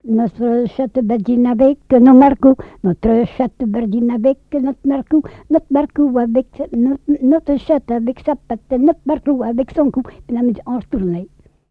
Couplets à danser
Pièce musicale inédite